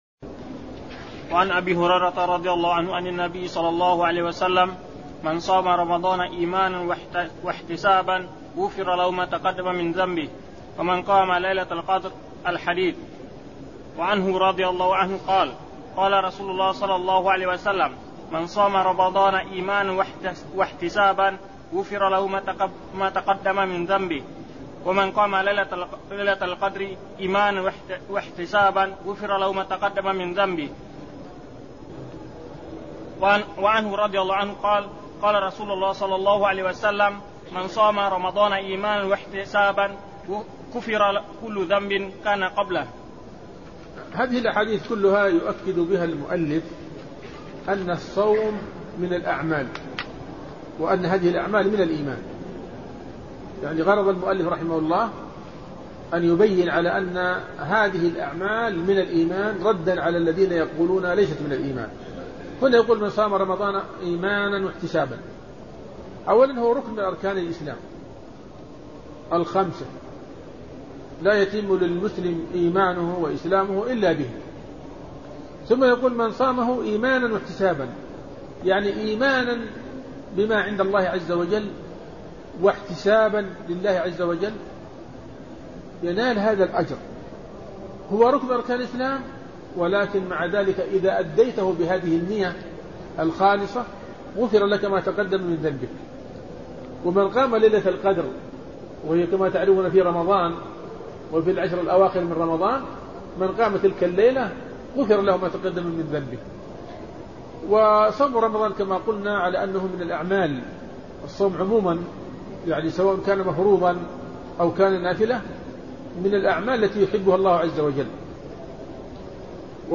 شرح الإيمان لابن منده الدرس 72